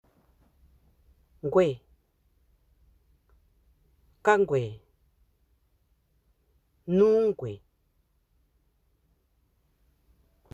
Accueil > Prononciation > gw > gw